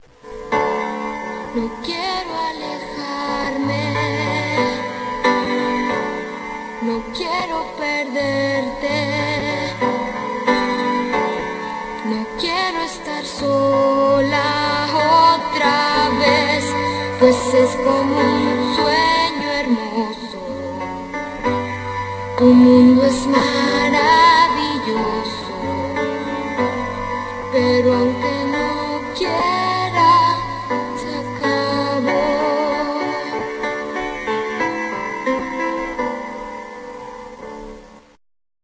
rock opera
guitar
drums
bass